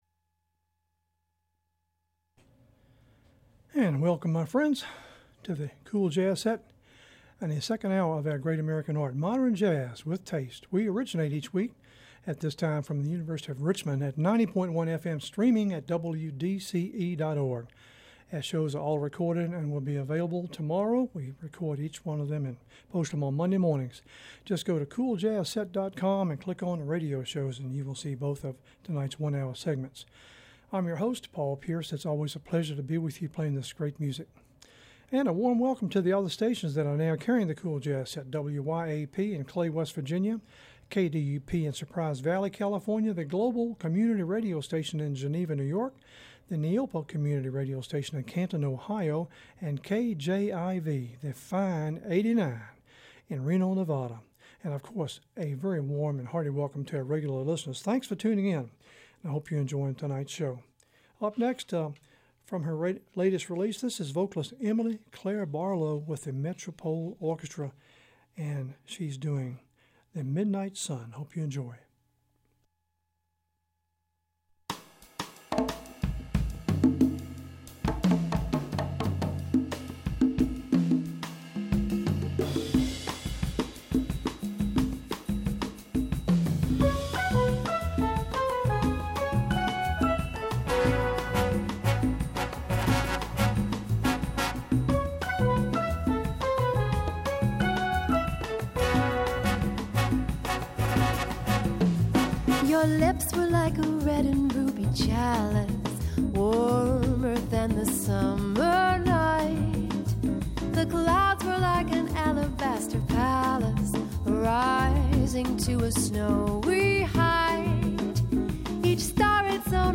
Swinging into Advent;12/6/15; set 2 Subtitle: cool jazz set Program Type: Unspecified Speakers: Version: 1 Version Description: Version Length: 1 a.m. Date Recorded: Dec. 6, 2015 1: 1 a.m. - 55MB download